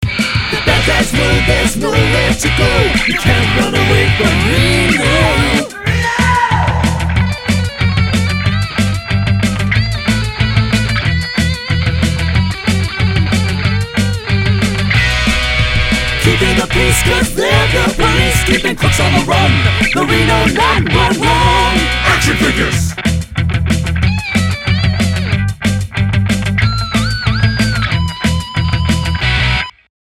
tv ad